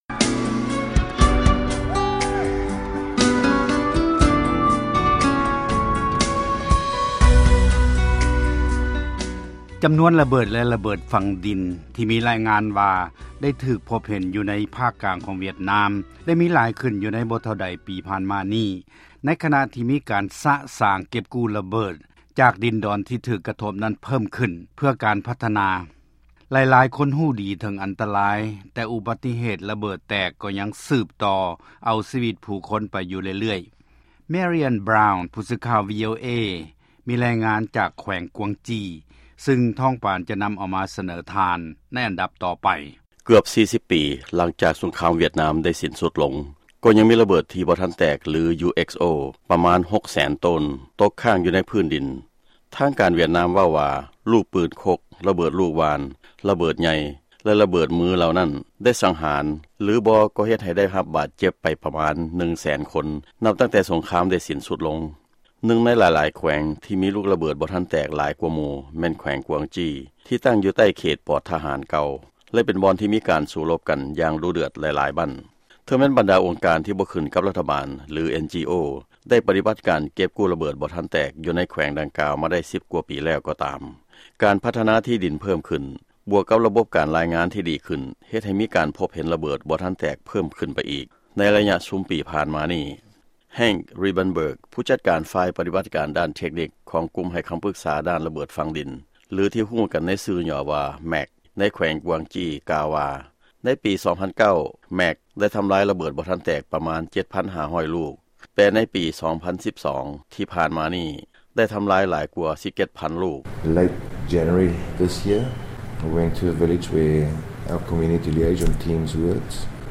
ຟັງລາຍງານກ່ຽວກັບລະເບີດ UXO ໃນຫວຽດນາມ